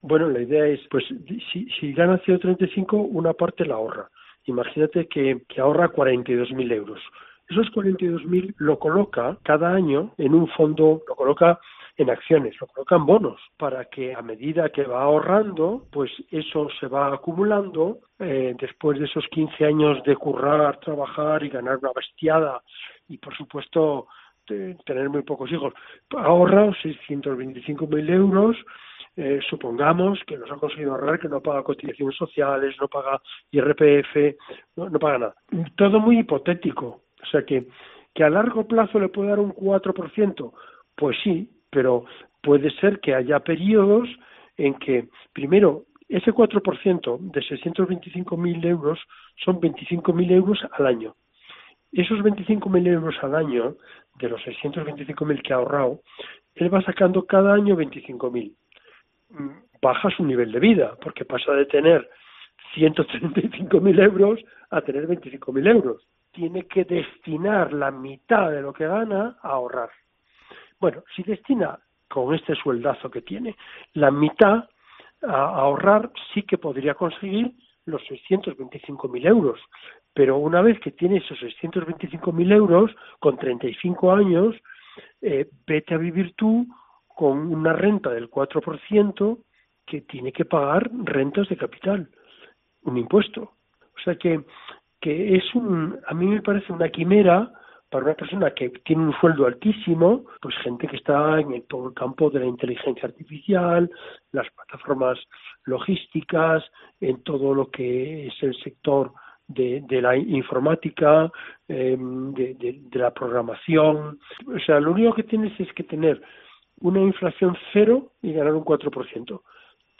catedrático de Economía, explica en qué consiste este método 'FIRE'